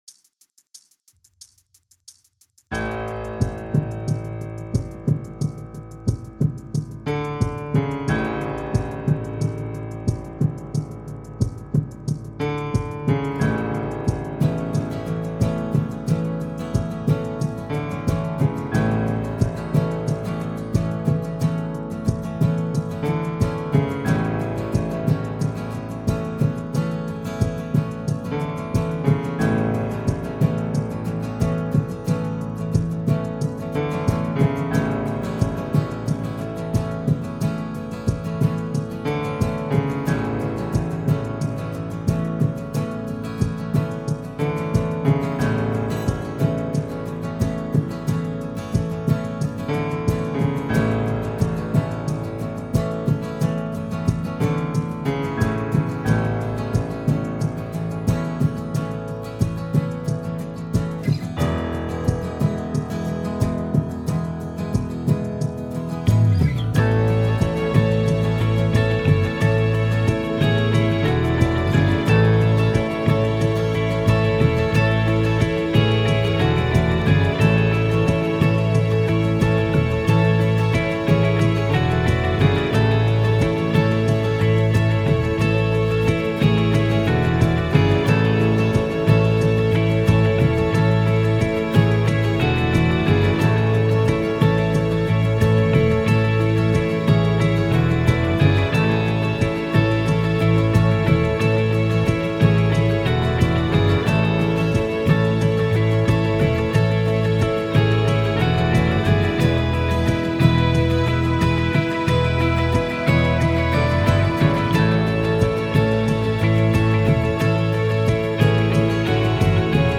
BPM : 90
Tuning : E
Without vocals